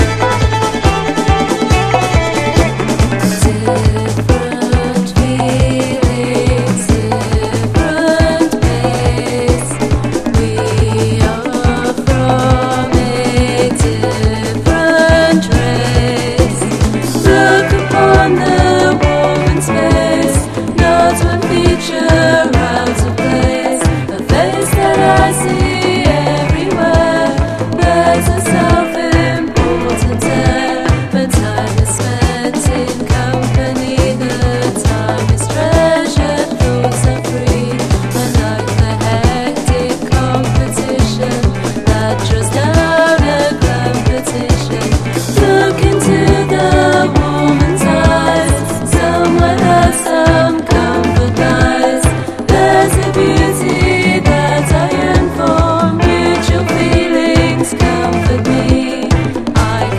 ジャジー・ネオアコ/ポストパンク重要曲多数！